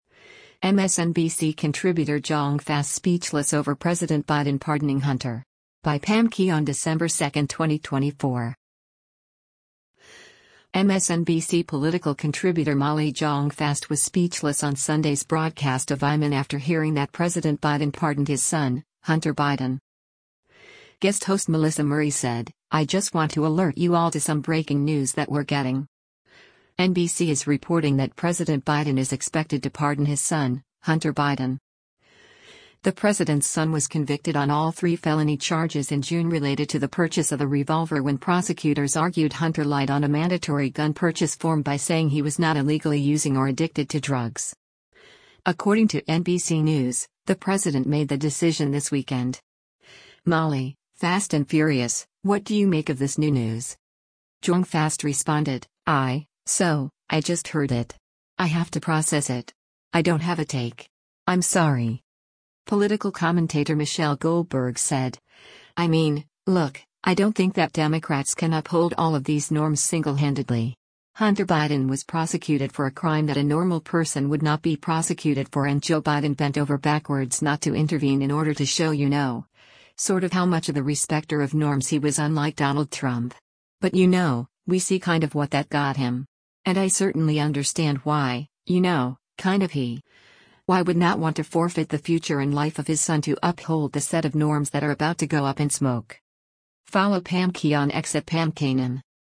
MSNBC political contributor Molly Jong-Fast was speechless on Sunday’s broadcast of “Ayman” after hearing that President Biden pardoned his son, Hunter Biden.